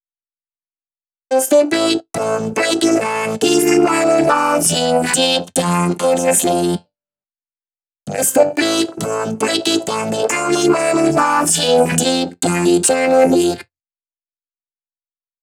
VTDS2 Song Kit 10 Pitched Mr Big Boom Vocoder.wav